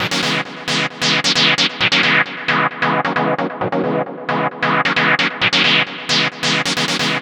Stab 133-BPM 1-C#.wav